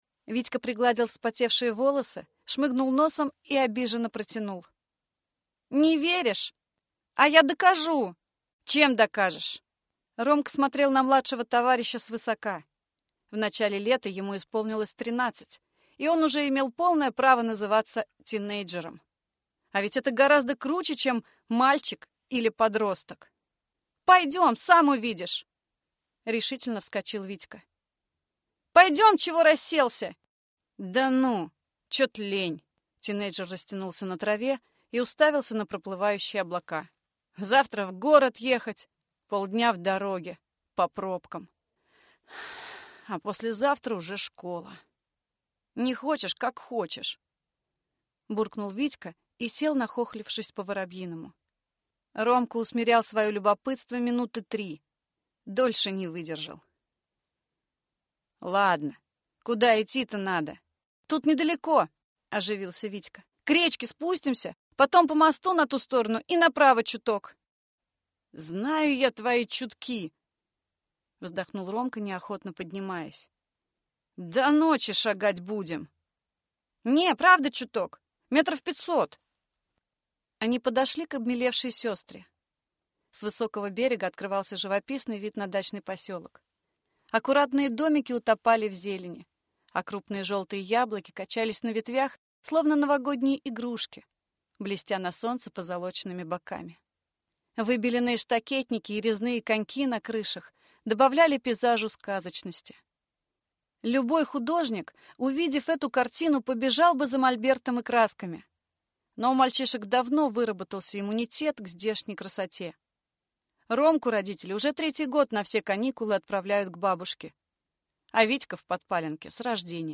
Аудиокнига «Краткость и талант». Альманах-2022 | Библиотека аудиокниг